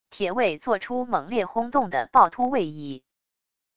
铁卫做出猛烈轰动的暴突位移wav音频